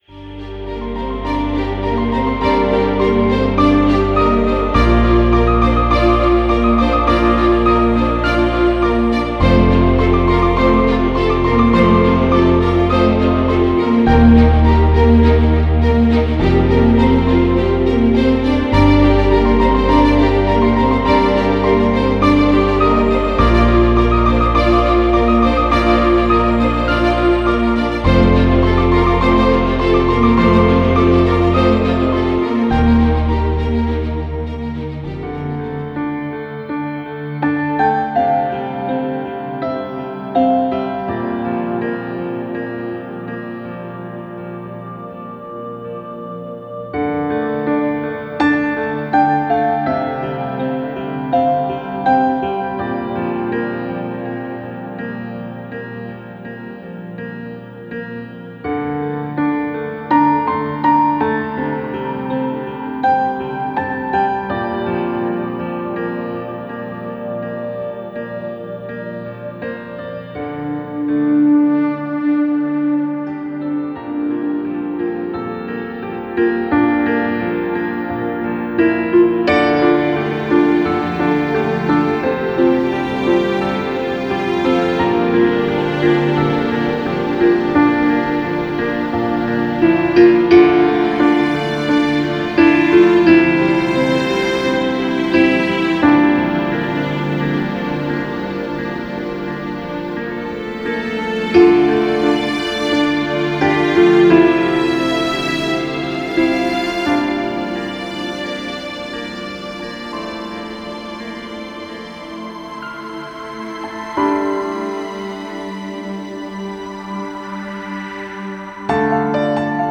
Jeden z krytyków tak określił jego muzykę: „Punktem kulminacyjnym filmu jest z pewnością dźwięk, od żwawej walki i ciężkich pomruków aż do muzyki, która brzmi jakby pochodziła z hollywoodzkiego hitu z lat 80”.